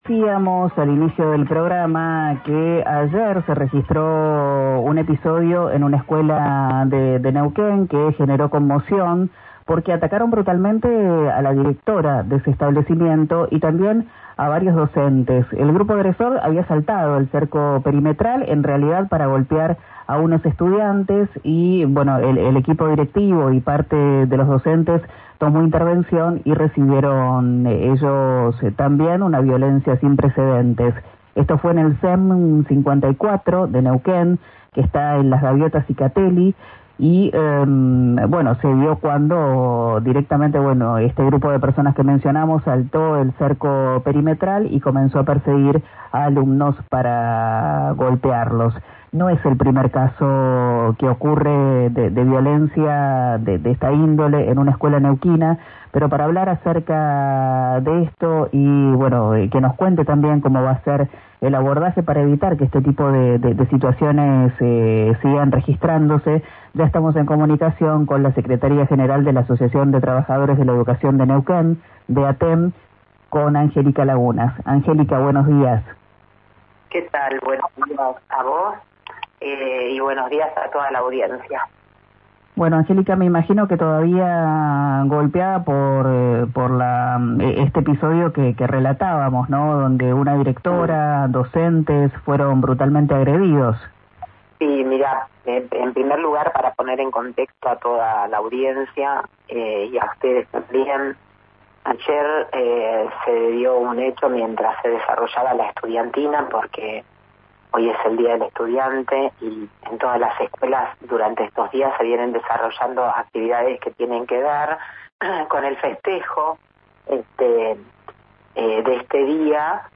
Desde ATEN Capital informaron acerca de la situación en RÍO NEGRO RADIO y exigieron una política preventiva y equipos interdisciplinarios.